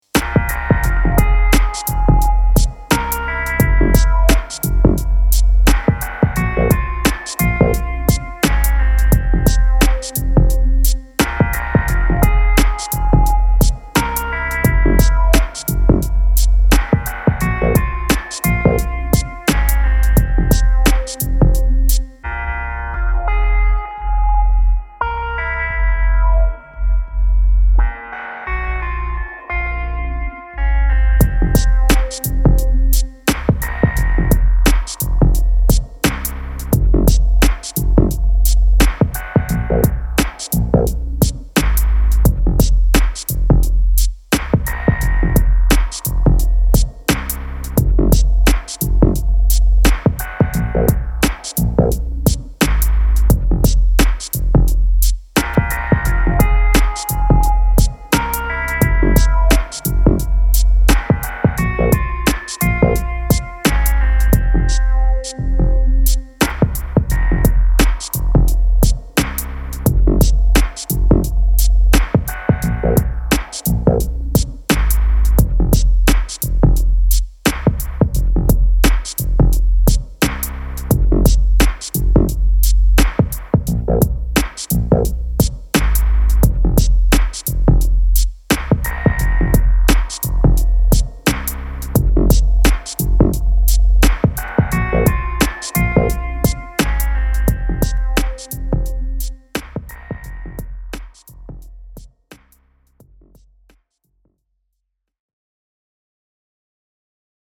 Minimalistic hip hop beat from the street with groove.